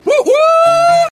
Geico Camel Humpday - Botón de Efecto Sonoro